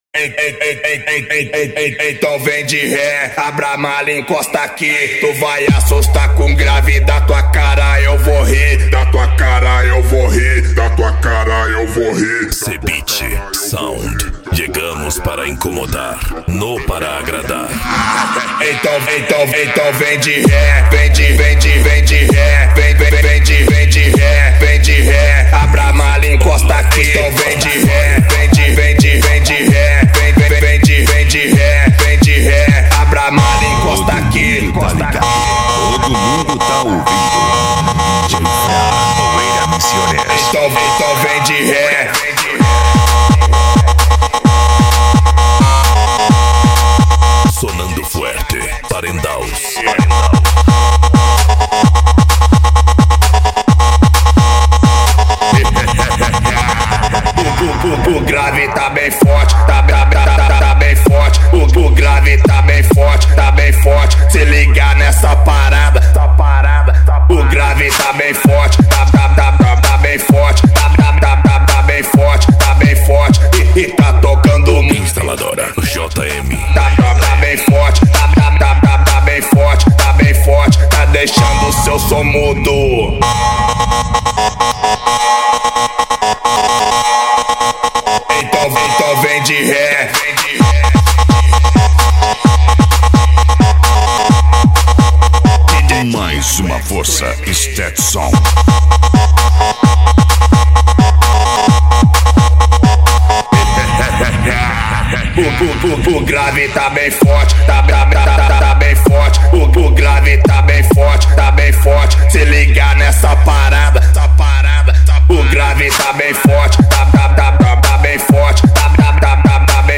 Remix
Racha De Som
Bass